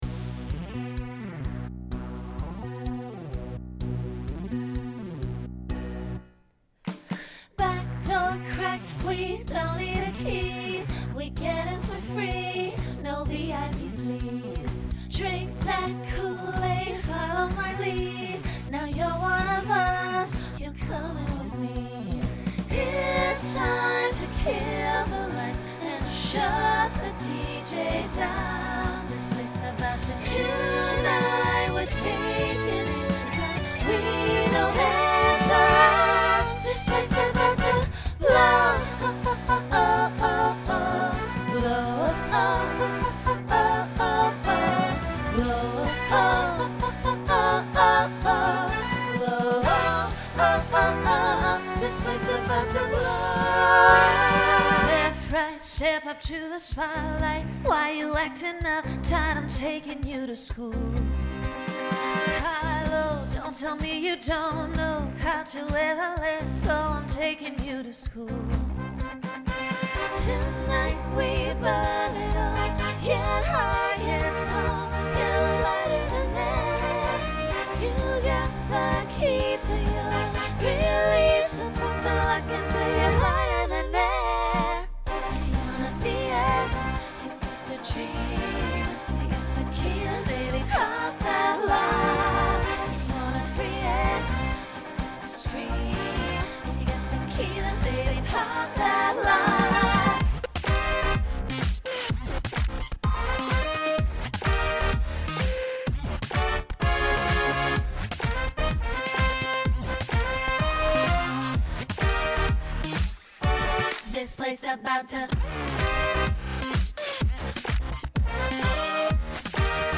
Includes dance break click track.
Genre Pop/Dance
Voicing SSA